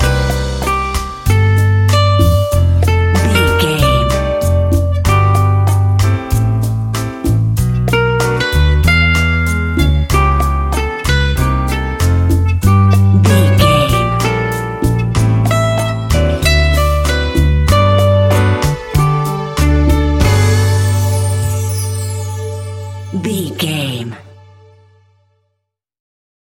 An exotic and colorful piece of Espanic and Latin music.
Ionian/Major
Slow
romantic
maracas
percussion spanish guitar